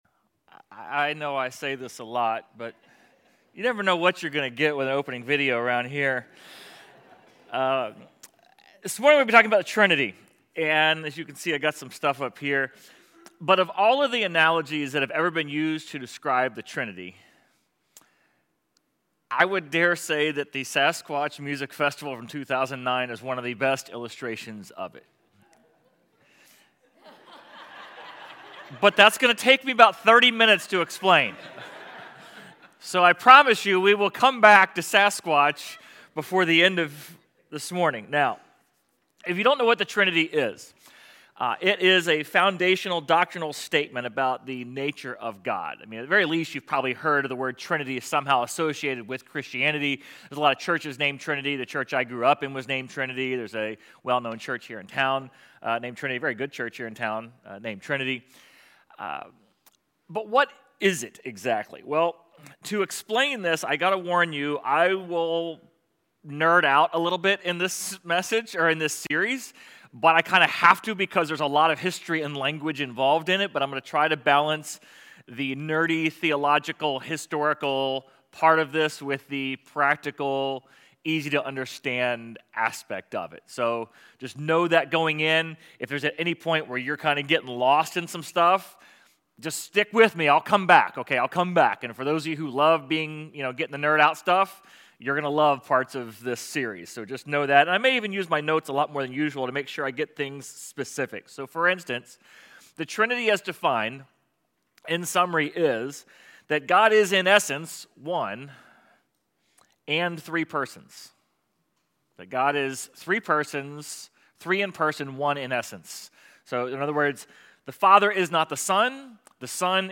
Sermon Audio/Video | Essential Church